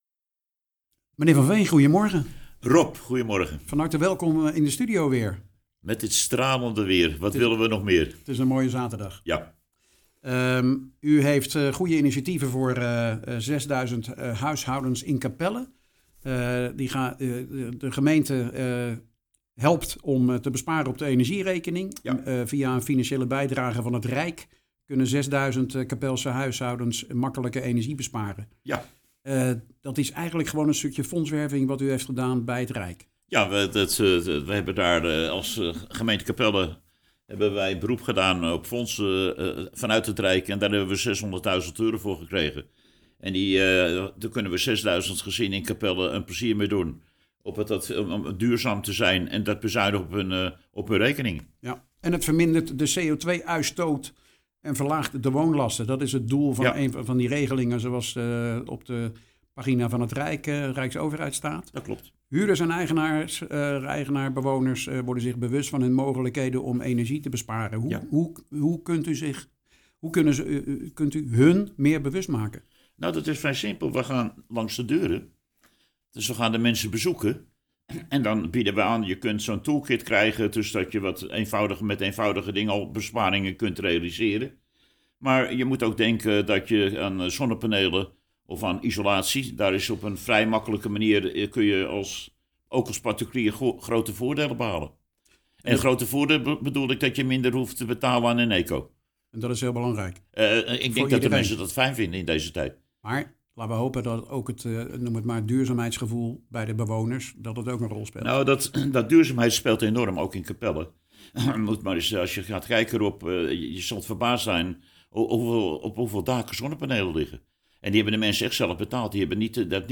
praat erover met wethouder Duurzaamheid Nico van Veen.